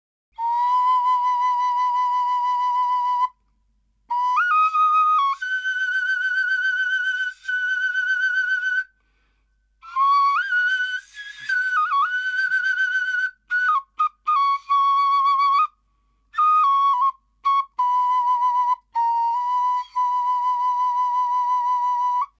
ななめ笛
中が貫通している竹筒です　本体をななめにして口笛を吹くように吹きます